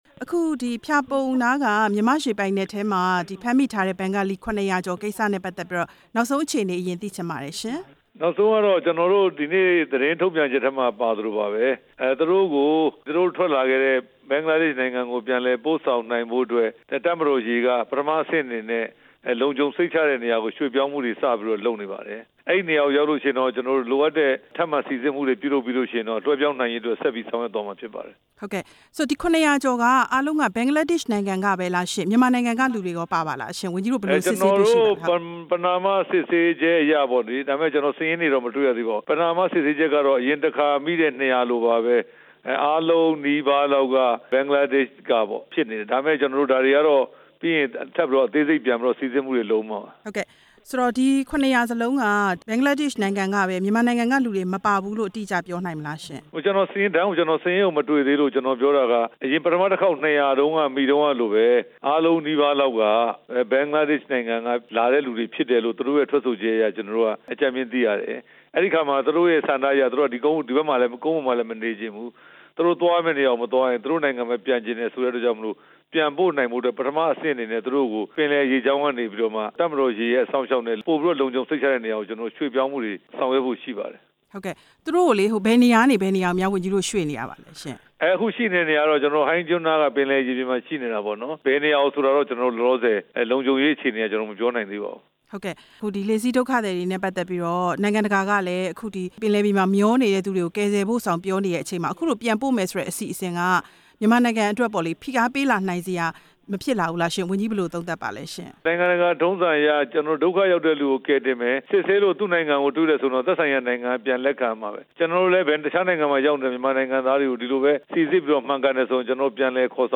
ပြန်ကြားရေးဝန်ကြီး ဦးရဲထွဋ်ကို မေးမြန်းချက်